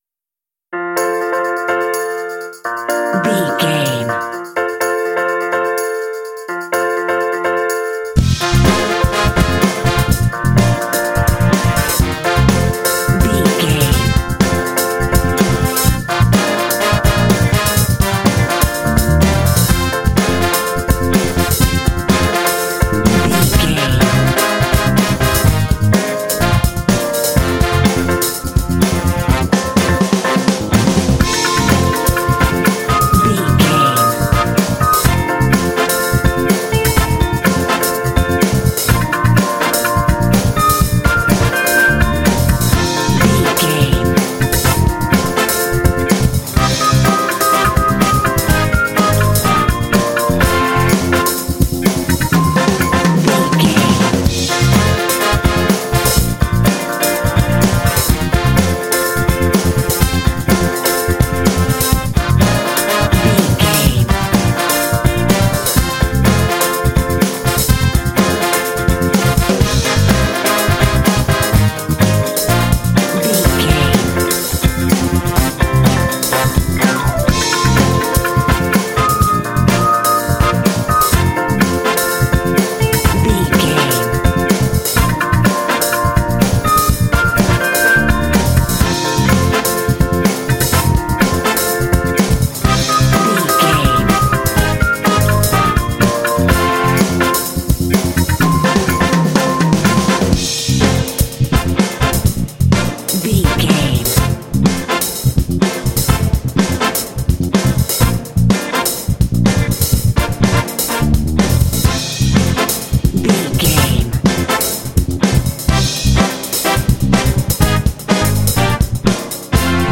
This funk track is reminiscent of 12-bar blues phrasing.
Uplifting
Ionian/Major
groovy
funky
driving
energetic
piano
percussion
drums
bass guitar
brass
electric guitar
electric organ